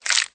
ripple_click_2.ogg